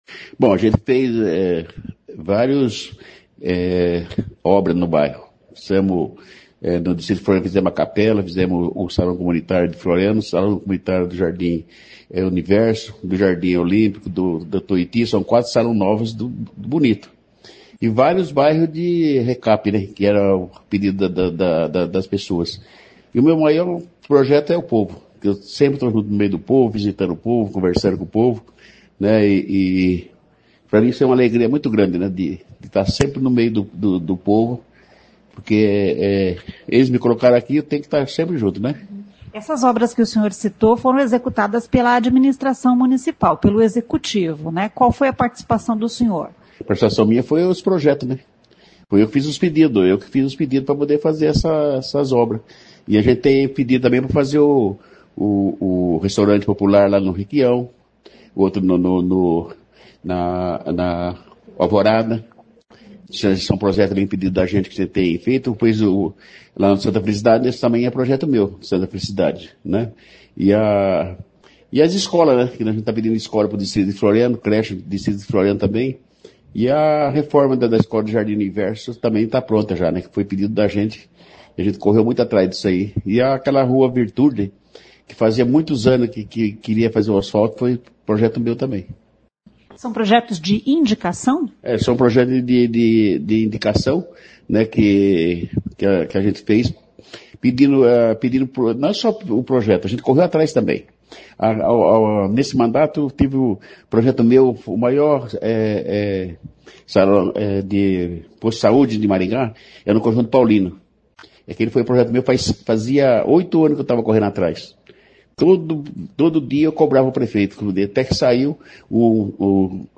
Série de Entrevistas